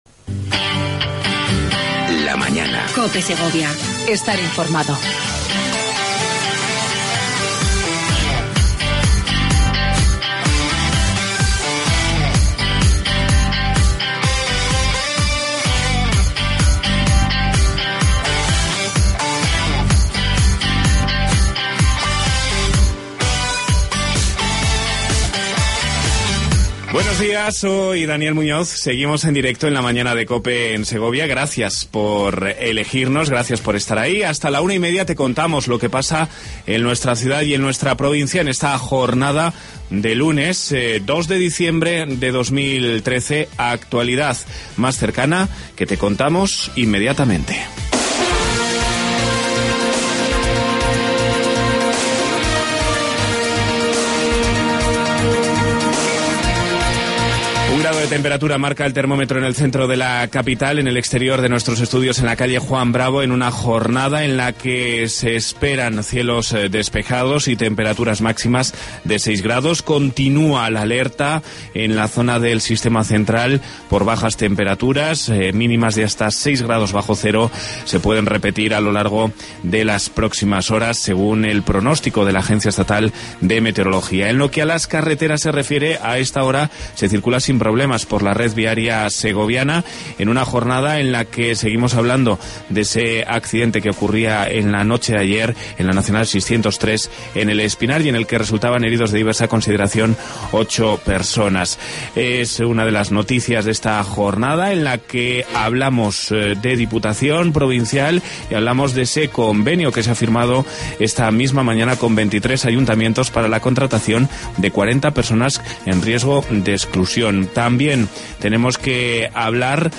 AUDIO: Entrevista a Francisco Vazquez, presidente de la Diputación Provincial de Segovia.